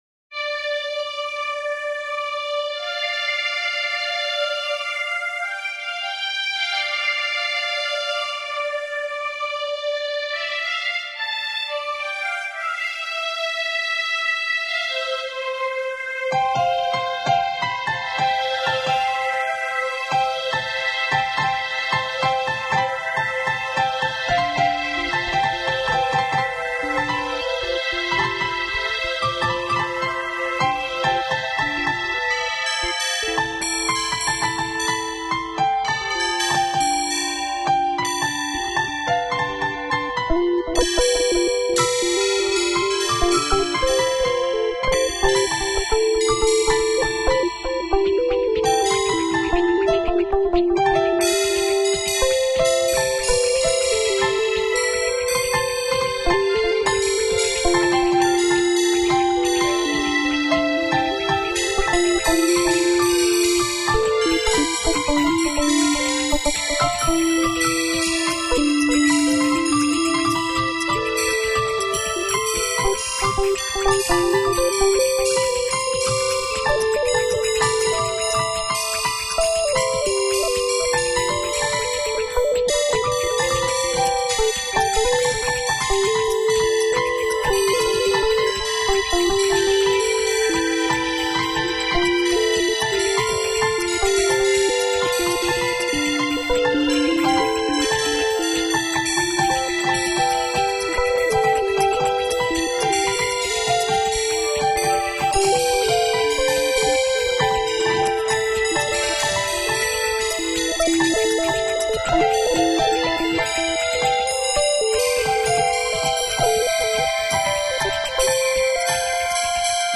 My Song fame_018 MELANCHOLY.m4a